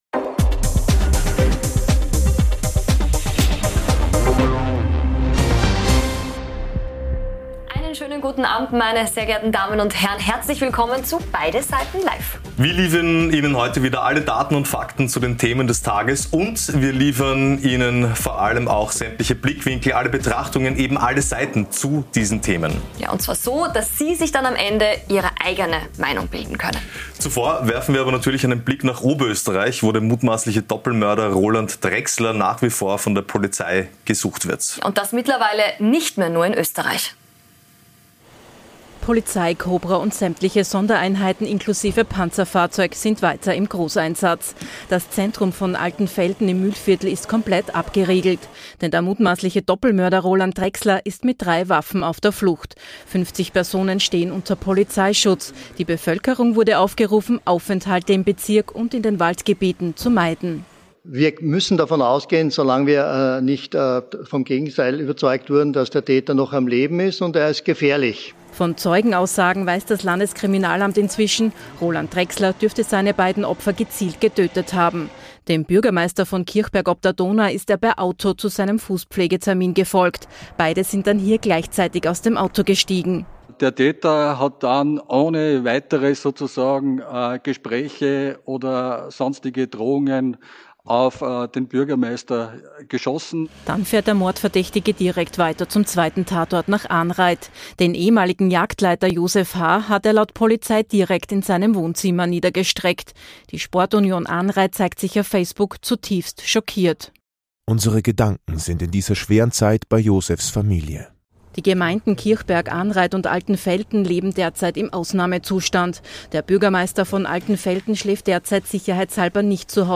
Schwarz-Rote Koalitionssuche: Migration ein Stolperstein? Nach Trump-Beleidigung: Entscheiden Latinos jetzt die US-Wahl? Und nachgefragt haben wir heute bei gleich zwei Gästen - im großen Beide Seiten Live Duell mit dem ehemaligen FPÖ-Parteichef Heinz-Christian Strache und ehemaliger SPÖ-Bundesgeschäftsführer - Thema: Erschwerte Regierungsbildung.